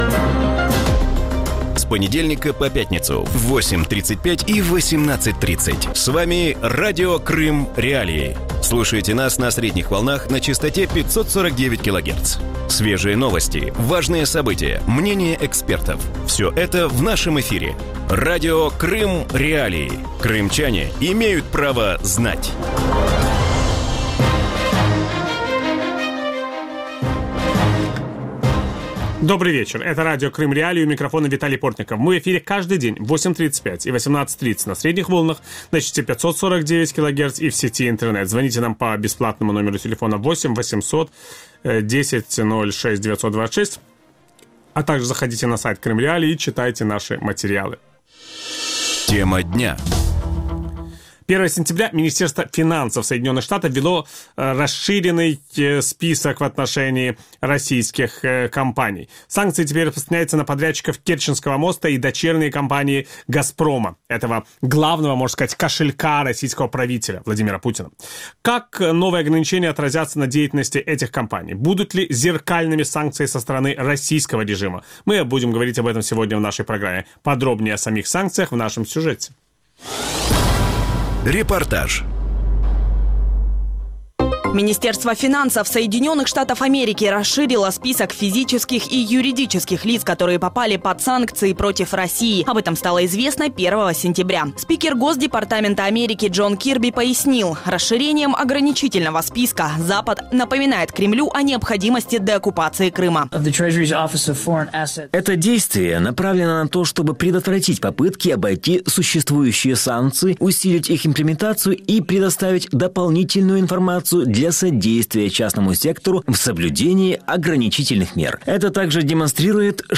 Ведучий: Віталій Портников.